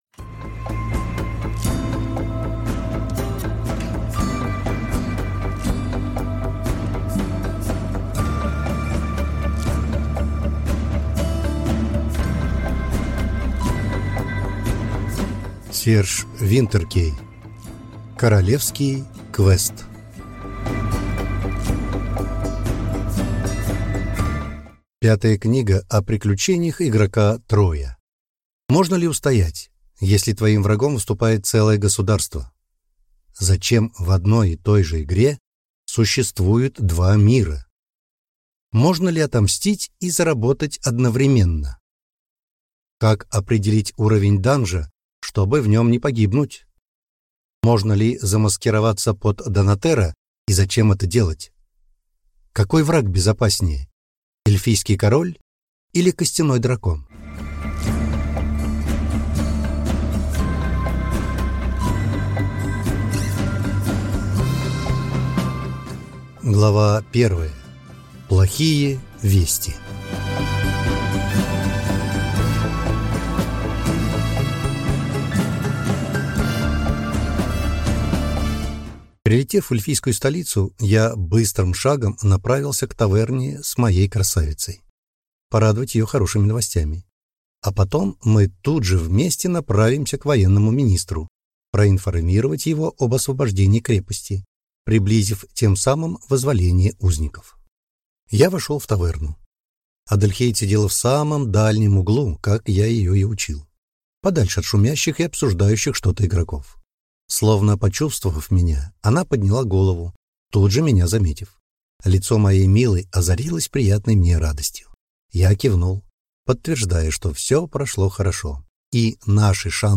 Аудиокнига Королевский квест | Библиотека аудиокниг